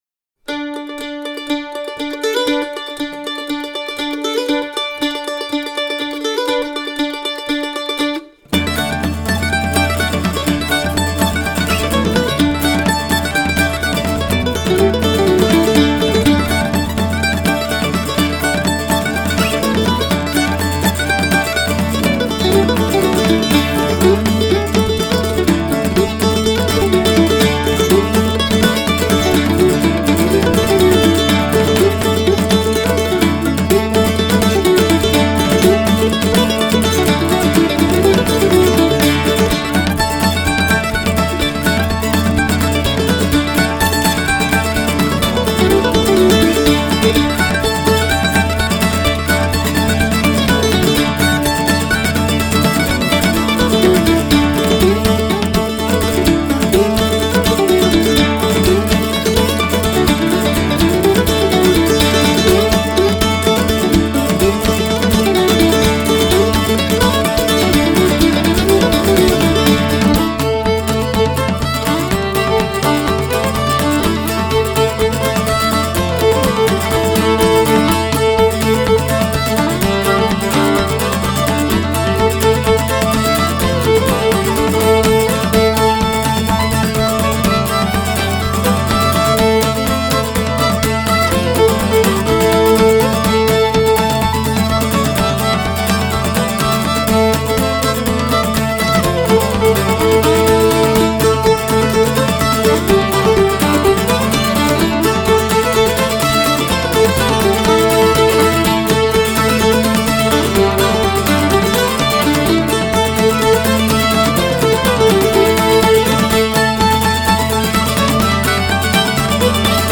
traditional music